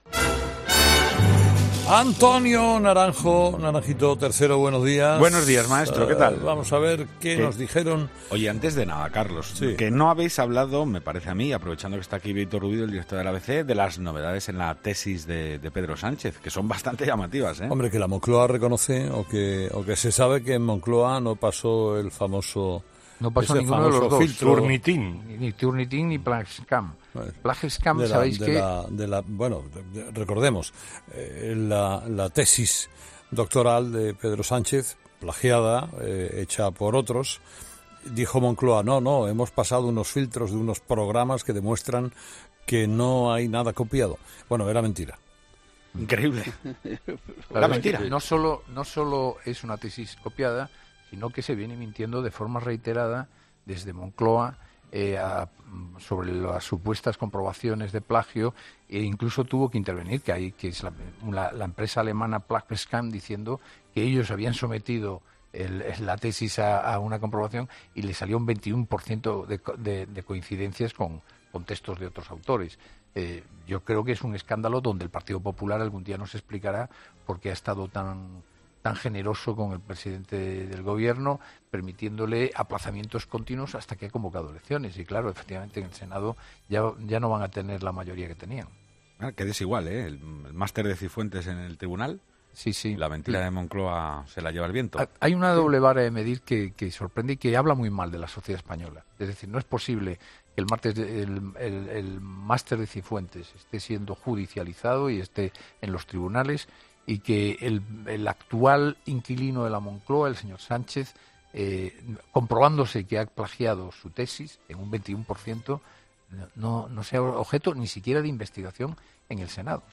La tertulia de los oyentes ha comenzado hoy con una reflexión de Carlos Herrera y de Bieito Rubido, director de ABC, tras conocerse esta noticia.